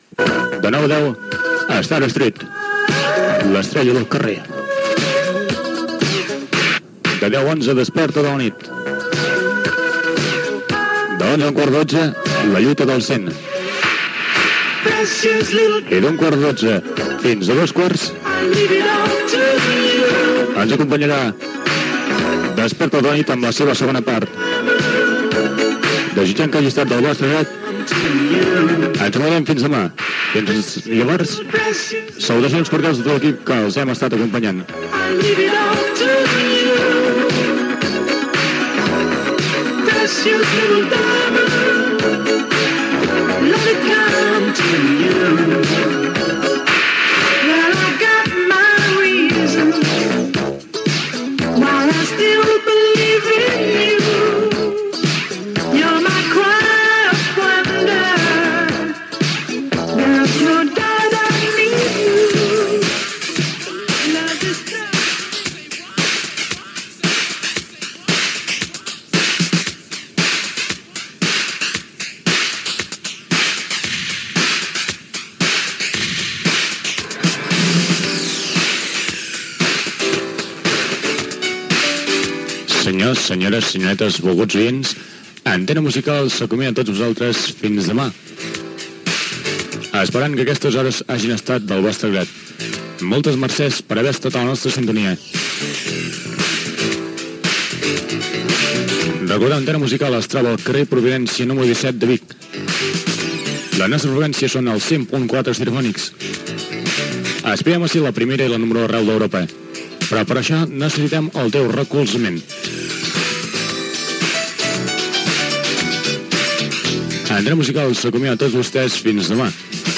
Programació de la ràdio, tema musical, tancament de l'emissió amb l'adreça de la ràdio
Musical
FM